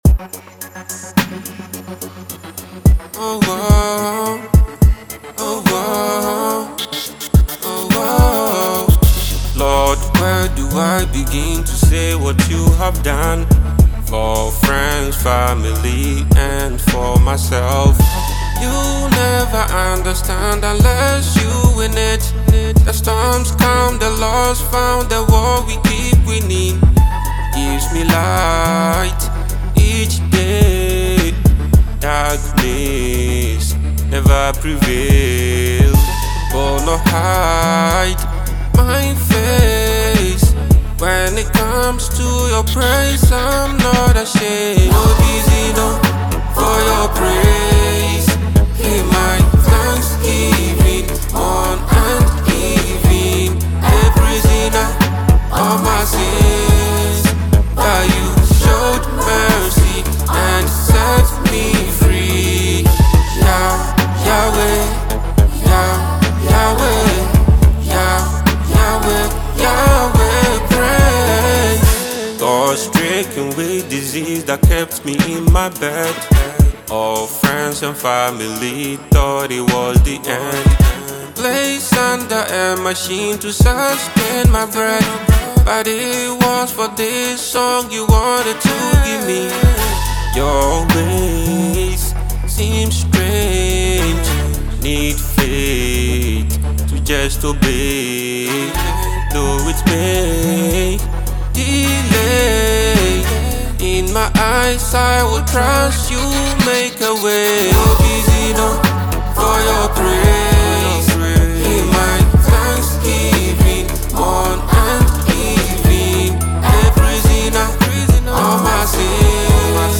soul-stirring Ghanaian gospel track
Ghanaian gospel singer and worship leader
• Genre: Gospel / Worship